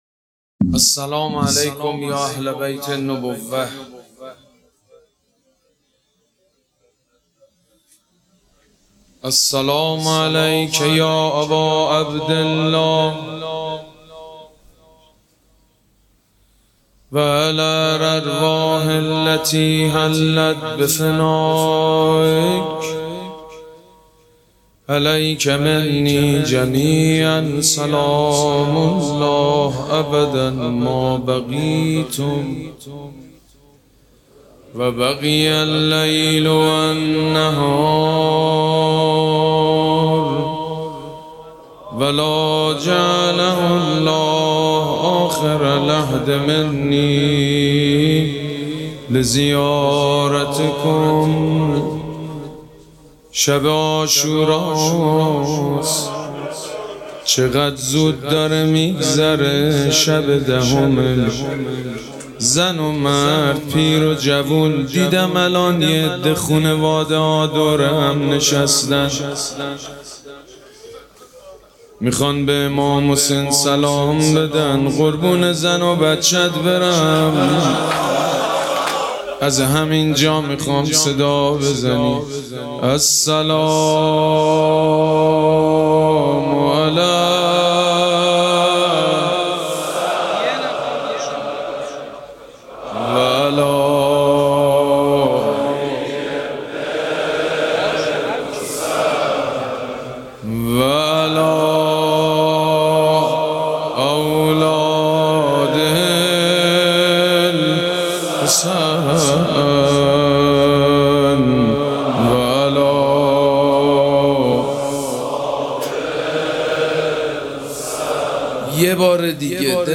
مراسم عزاداری شب دهم محرم الحرام ۱۴۴۷
مناجات